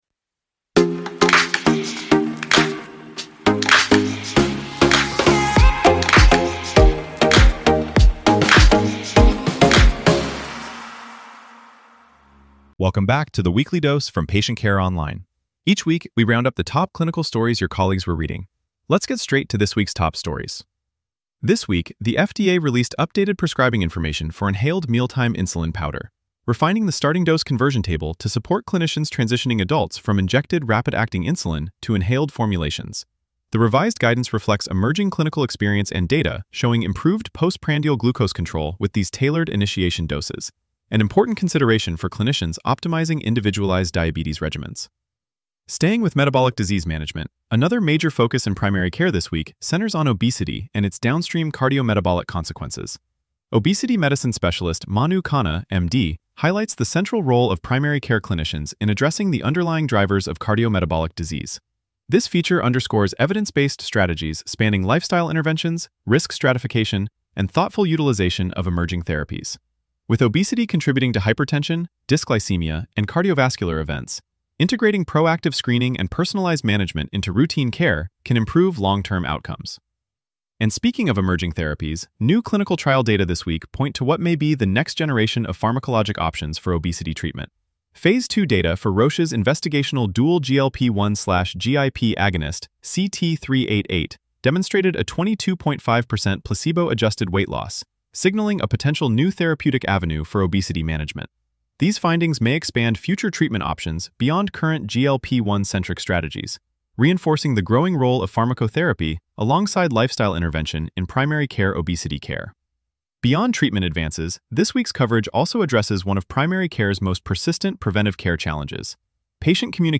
Primary Viewpoints from Patient Care Online features informative conversations with health care experts, opinion leaders, and practicing physicians, about what impacts primary care medicine today.